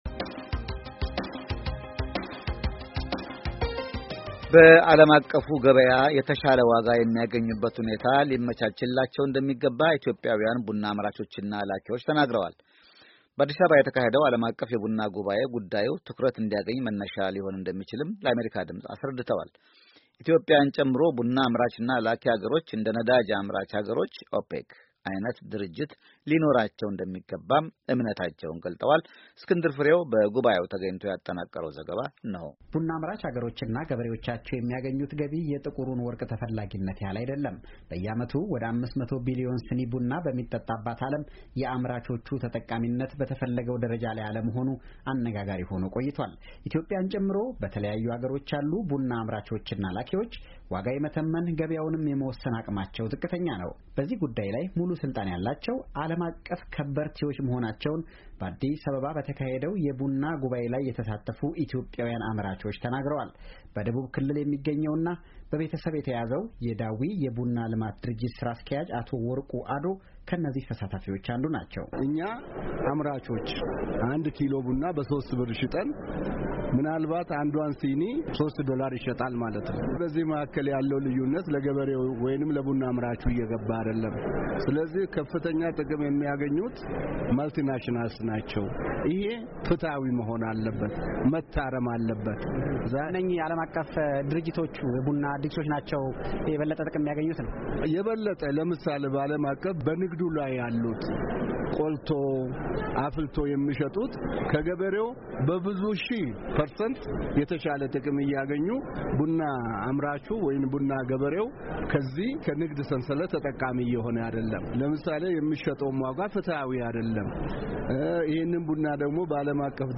በጉባዔው ተገኝቶ ያጠናቀረው ዘገባ አለ።